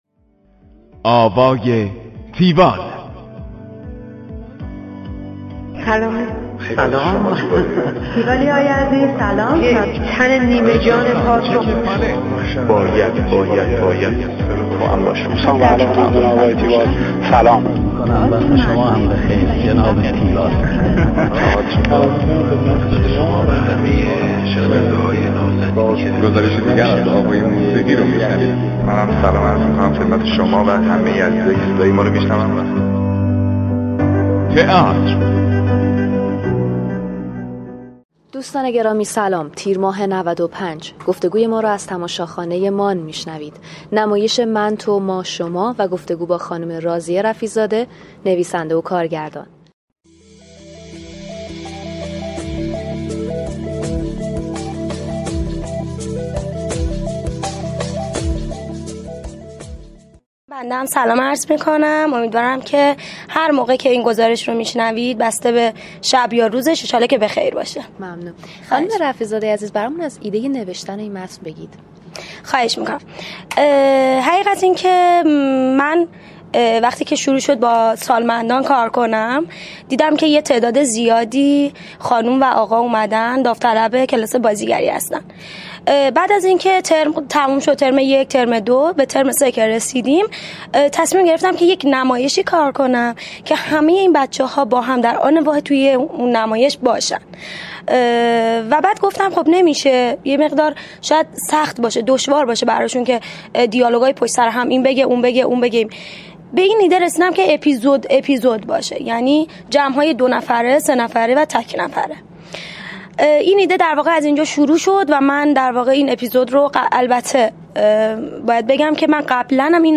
- یکی از اهداف بزرگ من پرداختن به مسایل مربوط به سالمندان در تئاتر است گفتگو کننده